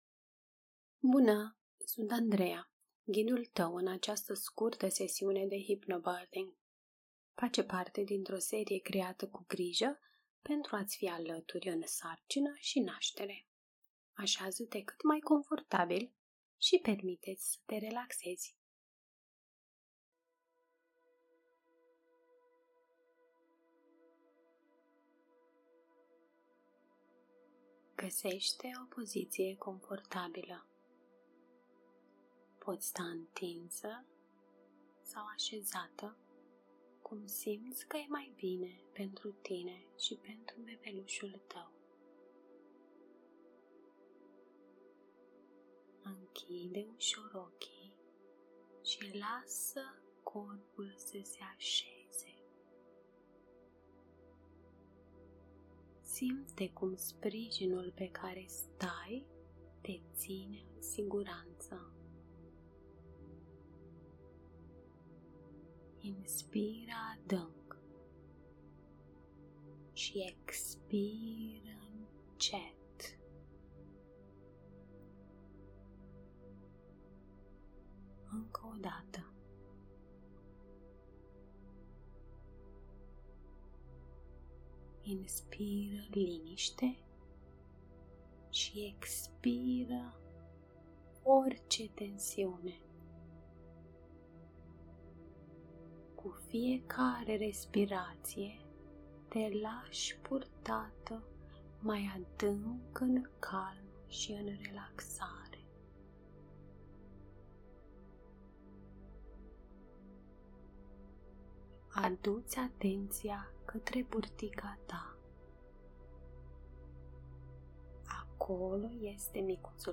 O meditație ghidată care te ajută să te calmezi, să reduci stresul și să intri într-o stare de prezență și liniște.